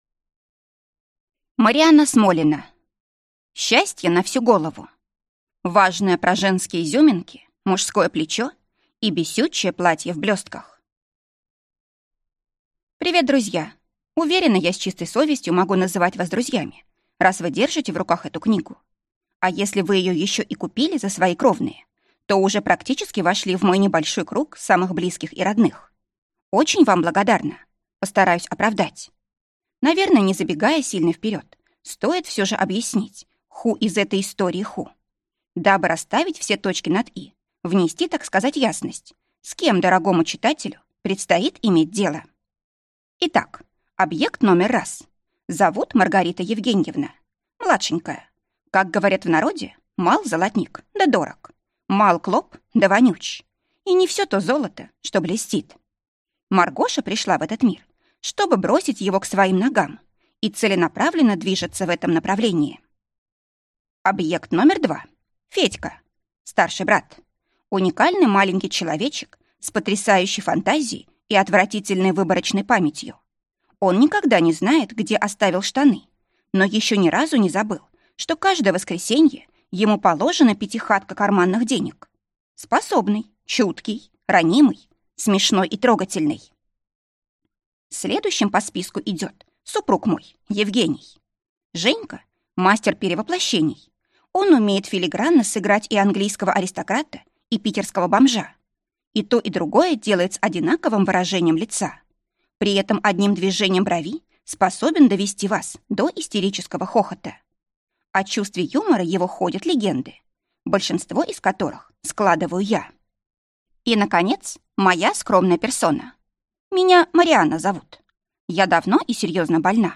Аудиокнига Счастье на всю голову. Важное про женские изюминки, мужское плечо и бесючее платье в блестках | Библиотека аудиокниг